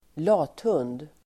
Ladda ner uttalet
lathund substantiv (vardagligt), lazybones [informal]Uttal: [²l'a:thun:d] Böjningar: lathunden, lathundarDefinition: lat person
lathund.mp3